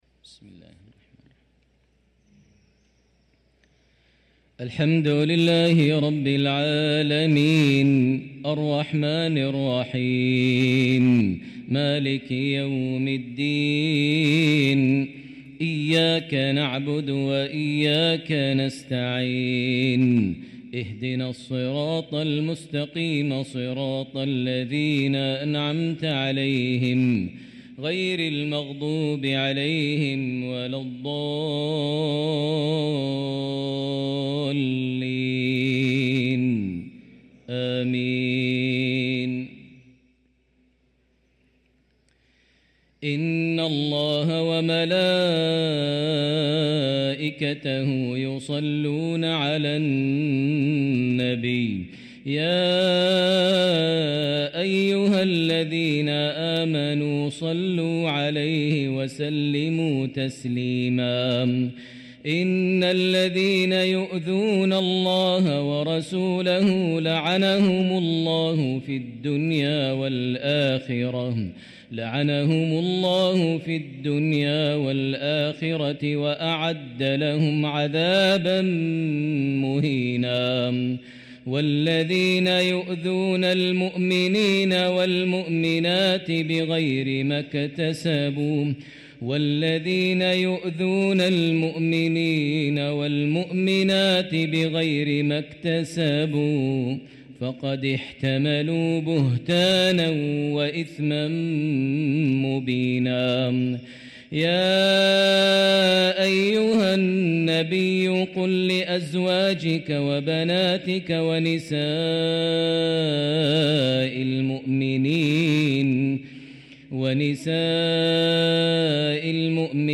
صلاة العشاء للقارئ ماهر المعيقلي 15 جمادي الآخر 1445 هـ
تِلَاوَات الْحَرَمَيْن .